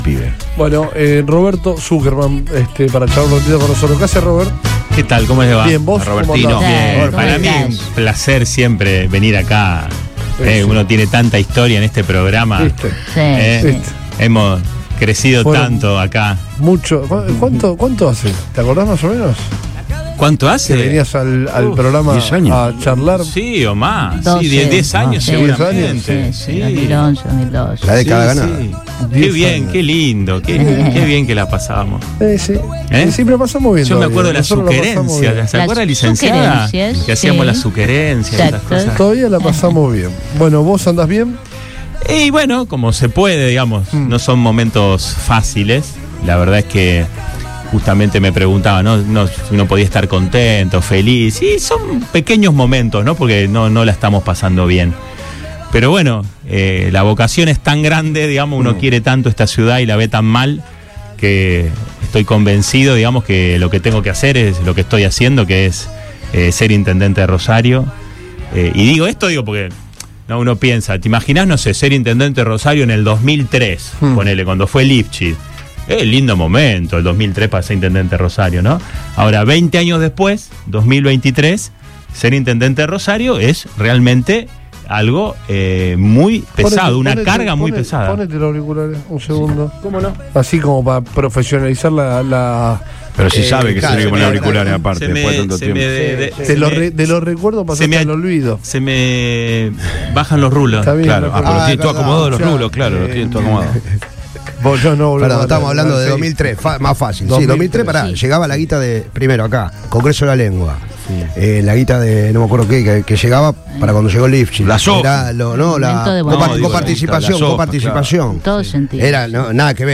El precandidato a intendente, Roberto Sukerman visitó los estudios de Radio Boing y charló